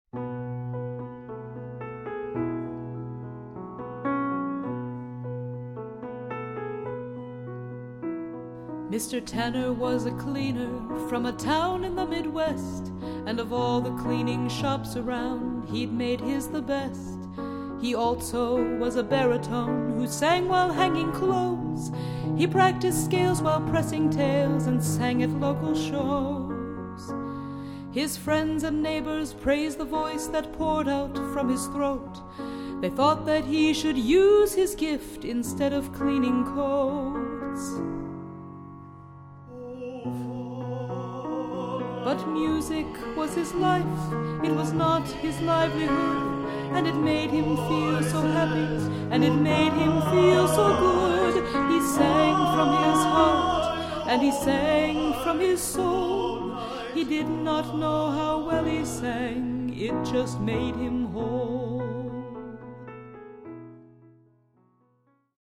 In-studio recordings: